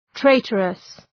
Προφορά
{‘treıtərəs}
traitorous.mp3